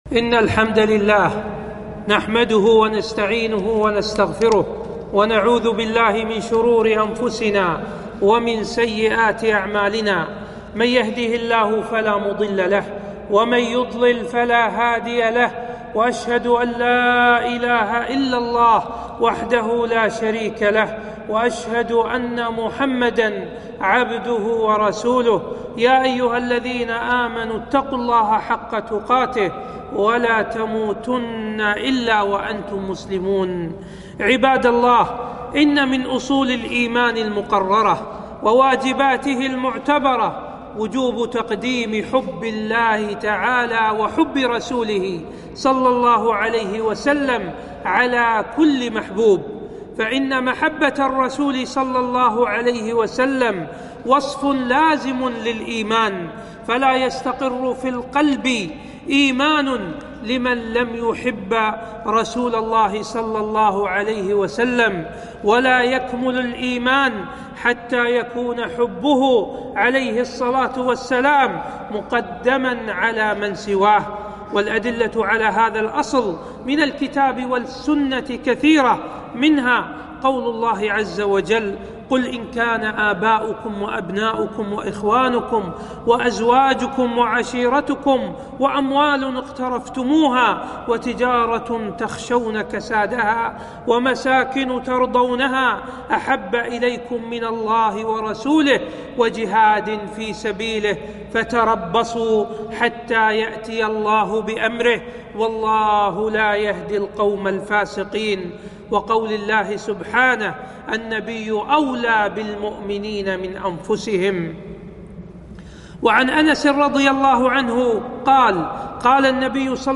خطبة - الاحتفال بالمولد النبوي بين صدق المحبة وبطلانها